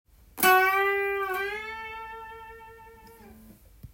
⑤のチョーキングは、ハーフチョーキングをしたあとに